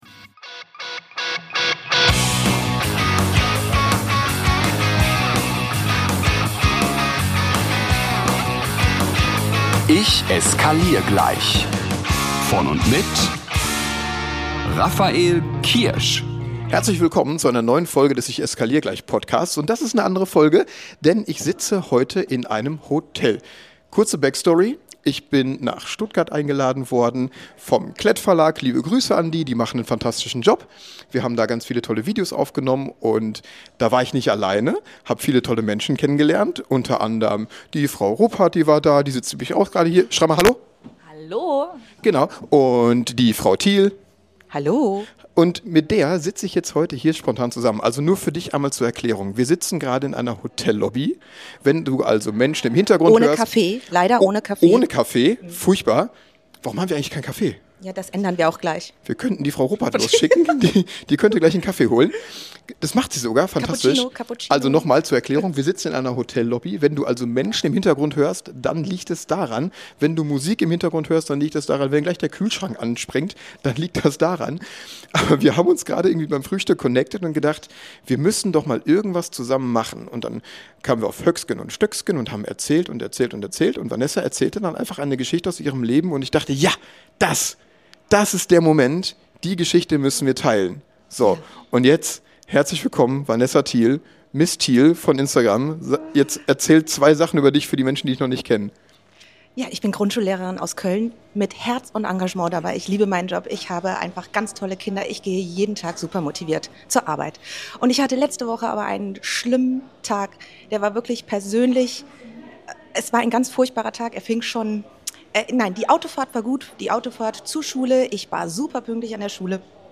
Mal ein ganz spontaner Podcast direkt aus der Hotellobby am Marienplatz in Stuttgart